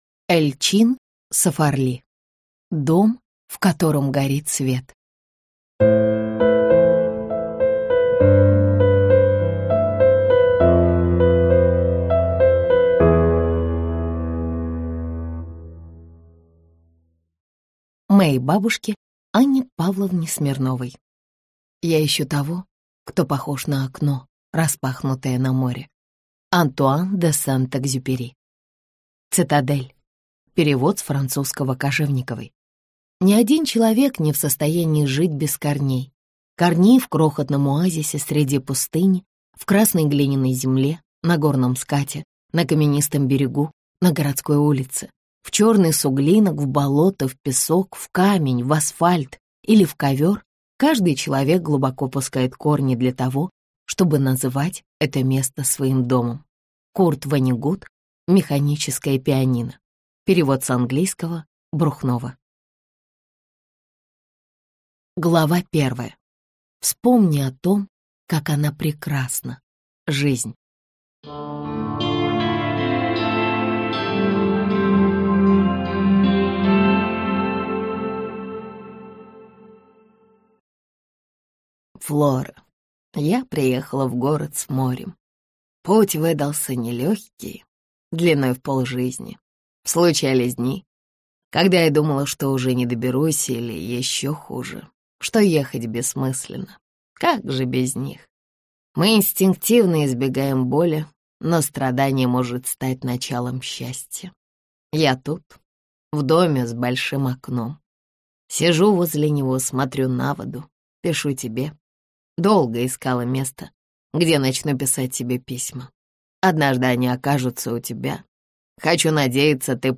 Аудиокнига Дом, в котором горит свет - купить, скачать и слушать онлайн | КнигоПоиск